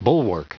Prononciation du mot bulwark en anglais (fichier audio)
Prononciation du mot : bulwark